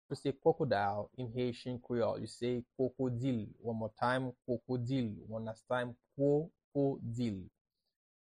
How to say “Crocodile” in Haitian Creole – “Kwokodil” pronunciation by a native Haitian teacher
“Kwokodil” Pronunciation in Haitian Creole by a native Haitian can be heard in the audio here or in the video below:
How-to-say-Crocodile-in-Haitian-Creole-–-Kwokodil-pronunciation-by-a-native-Haitian-teacher.mp3